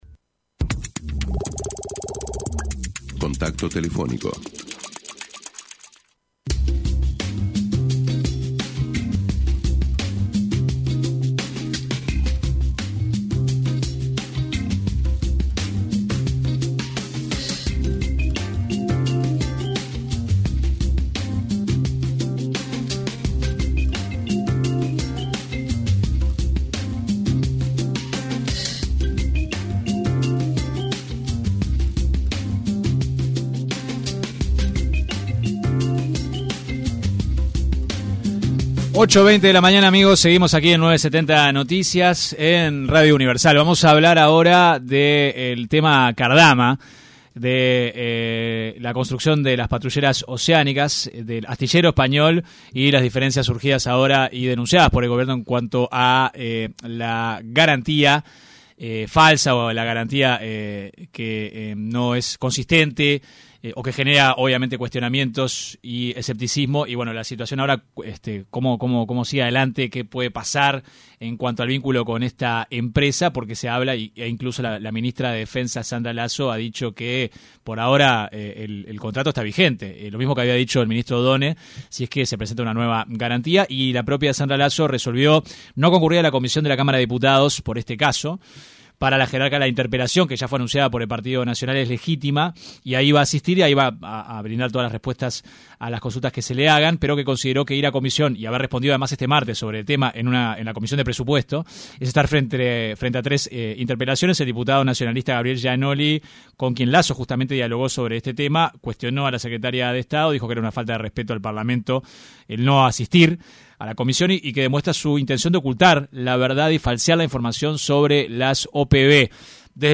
AUDIO El senador del Frente Amplio, Nicolás Viera, se refirió en diálogo con 970 Noticias, a los dichos del legislador nacionalista, Javier García, quien sostuvo que la decisión del Gobierno que busca rescindir el contrato de la construcción de las patrulleras oceánicas por parte de Cardama, beneficia a los narcotraficantes.